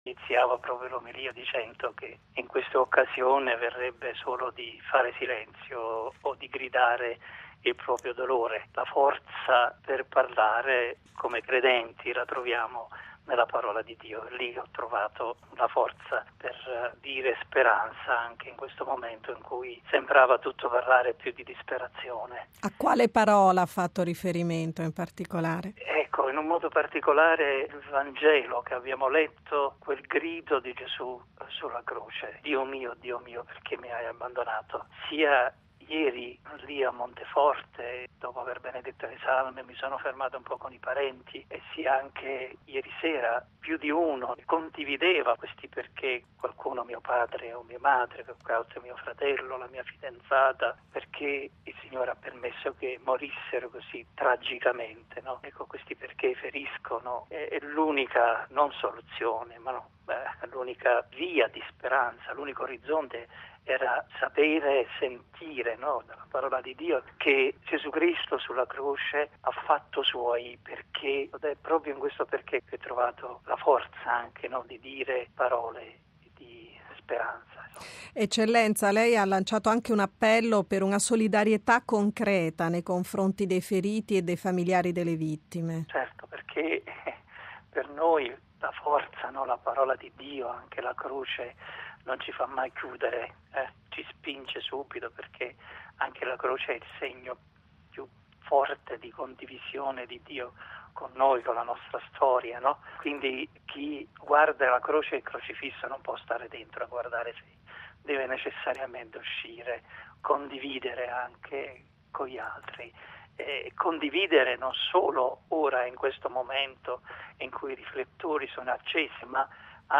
ha raggiunto telefonicamente per un commento il vescovo di Pozzuoli, mons. Gennaro Pascarella